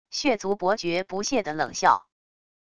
血族伯爵不屑地冷笑wav音频